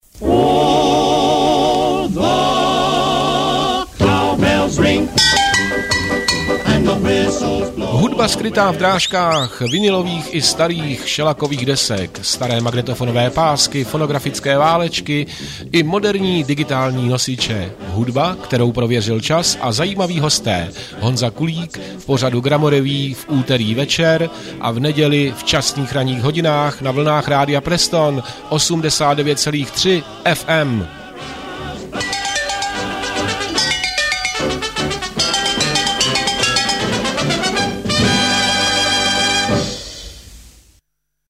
Upoutávky jsou v podstatě delšími jingly, sloužící jako promotion, většinou pravidelného pořadu.
Upoutávka na druhou sérii pořadu do čísla #163.